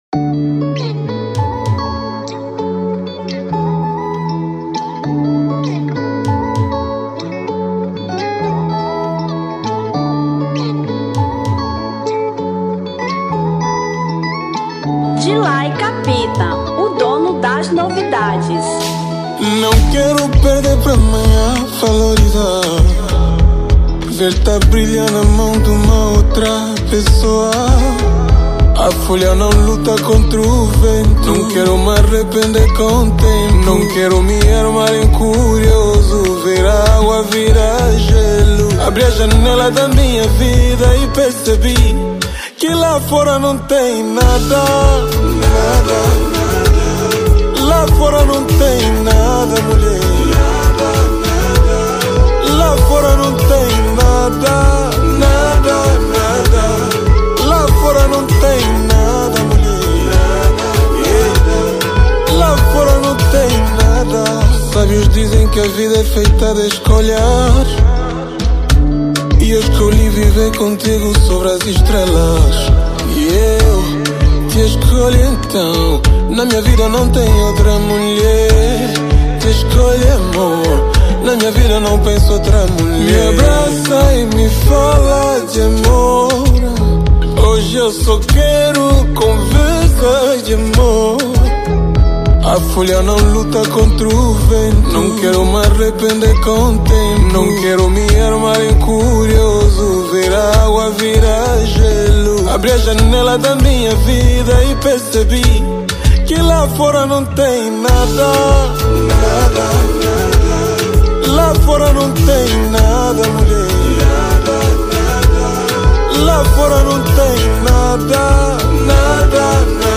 Zouk 2024